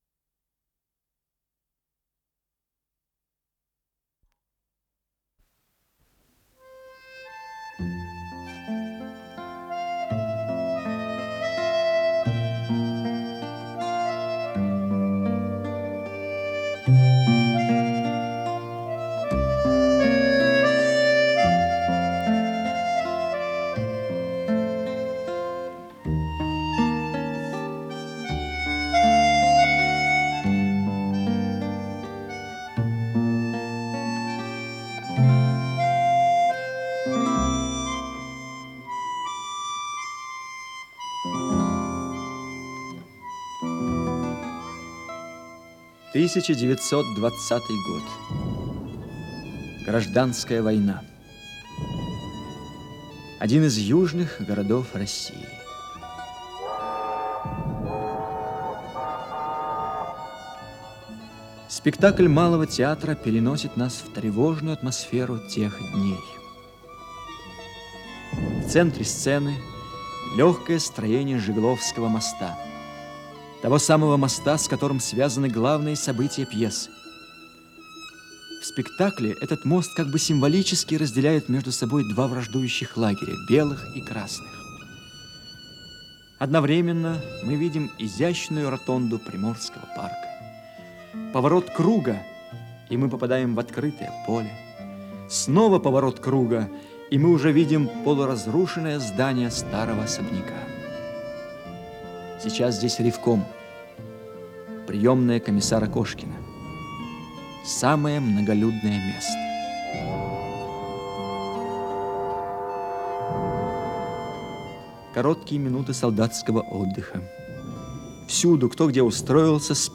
Исполнитель: Артисты Государственного академического Малого театра СССР
Радиокомпозиция спектакля